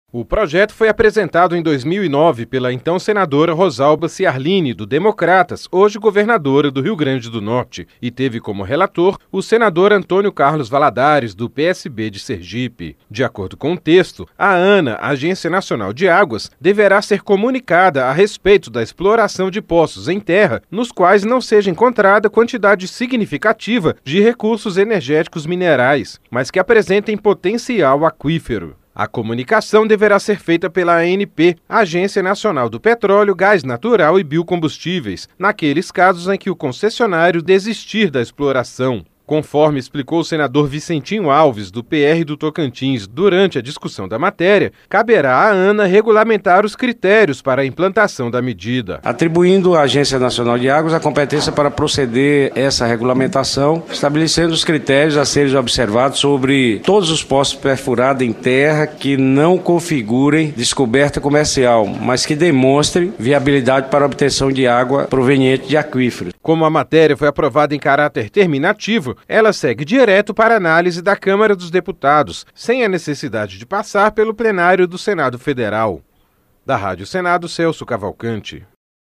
LOC: A DETERMINAÇÃO ESTÁ EM PROJETO APROVADO NESTA QUINTA-FEIRA PELA COMISSÃO DE SERVIÇOS DE INFRAESTRUTURA.